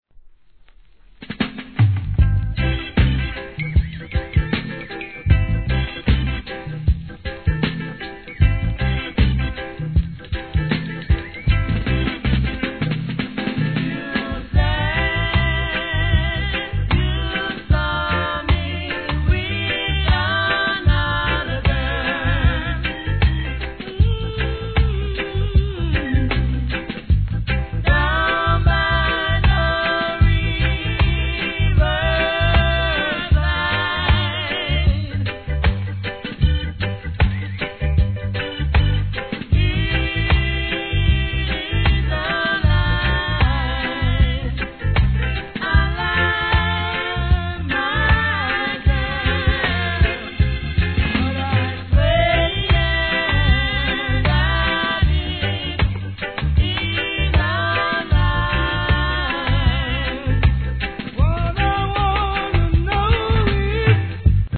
REGGAE
ベテランの味のあるコーラス・ワーク!!